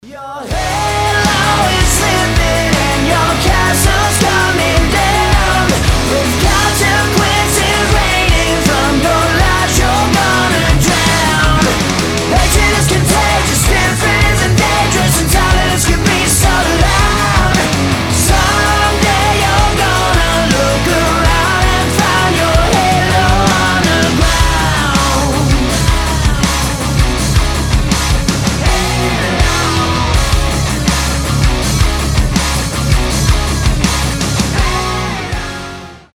мужской вокал
громкие
мощные
Драйвовые
Alternative Rock
post-grunge